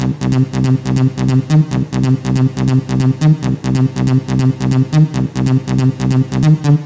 描述：高截止的舞蹈合成器调子BPM120高截止的舞蹈合成器。
标签： 电子 合成器 psytrance TECHNO 迷幻 房子 舞蹈 低音
声道立体声